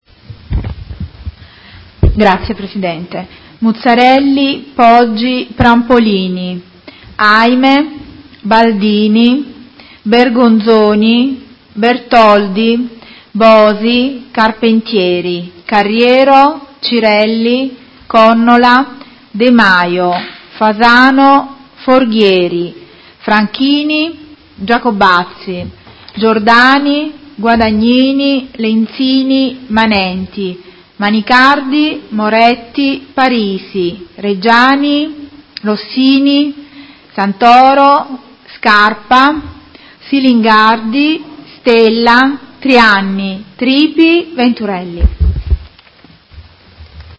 Seduta del 10/10/2019 Appello.